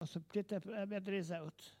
Enquête Arexcpo en Vendée
Locution